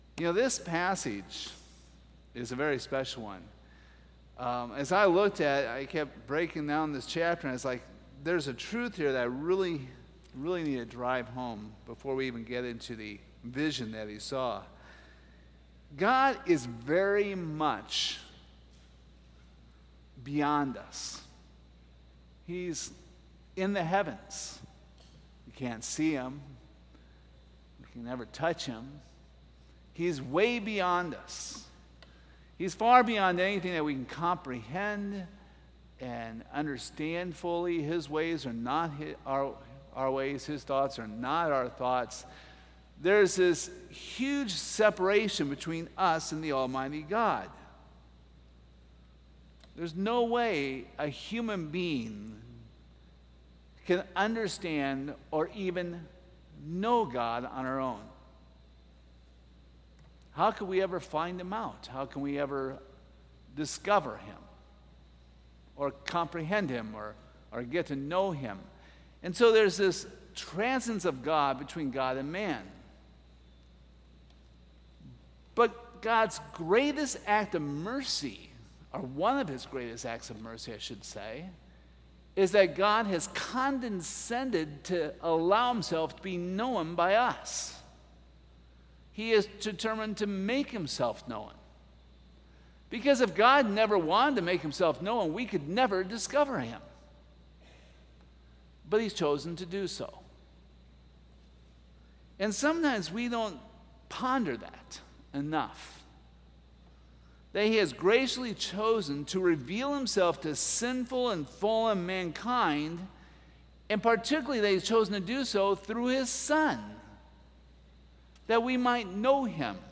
Service Type: Sunday Evening Topics: God's Sovereignty , God's Wisdom